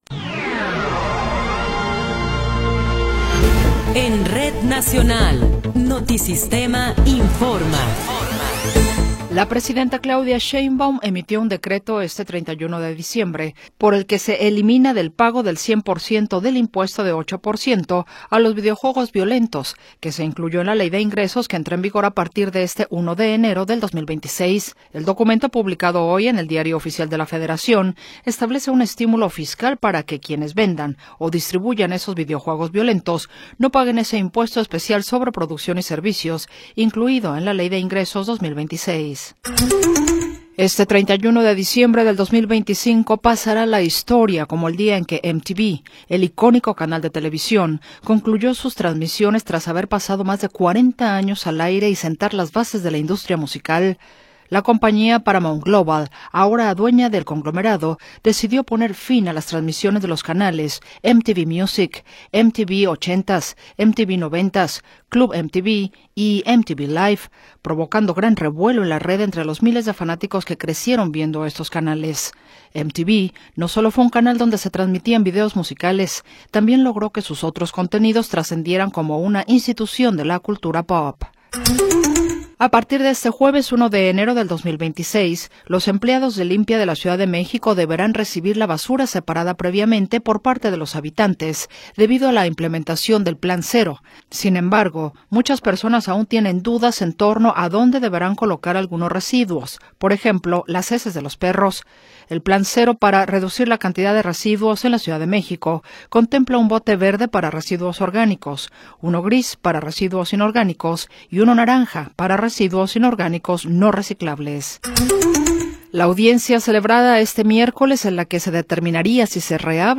Noticiero 17 hrs. – 31 de Diciembre de 2025
Resumen informativo Notisistema, la mejor y más completa información cada hora en la hora.